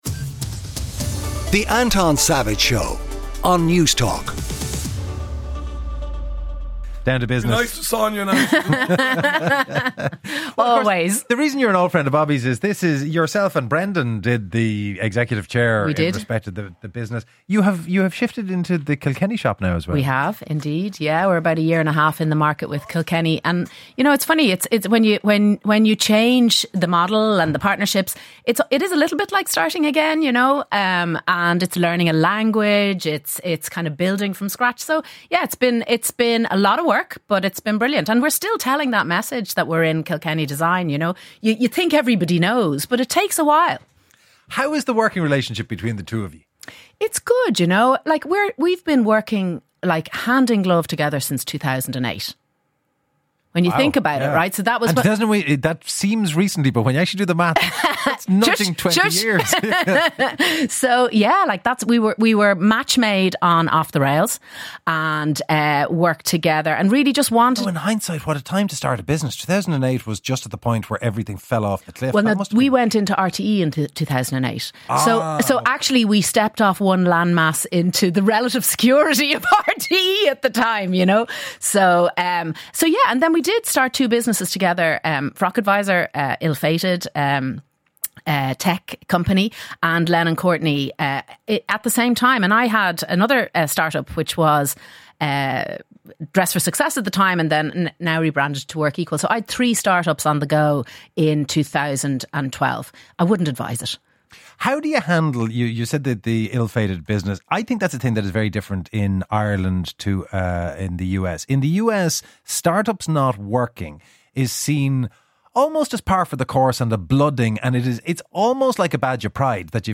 CONVERSATION THAT COUNTS | Ireland’s national independent talk station for news, sport, analysis and entertainment